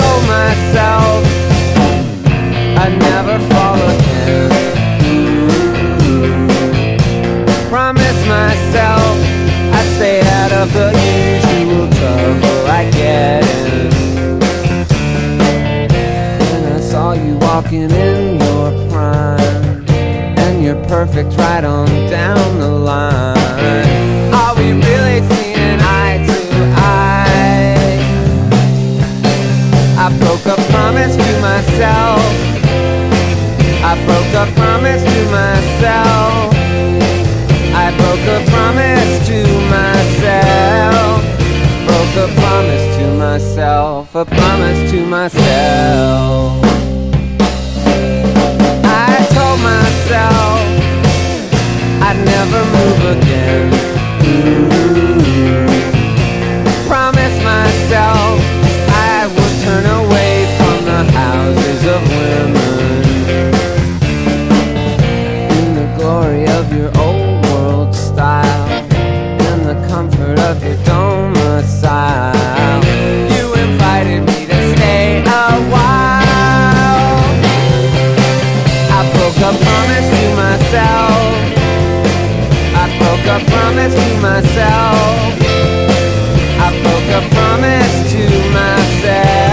INDUSTRIAL / NOISE / EXPERIMENTAL
オカルティック・インダストリアル・ノイズ！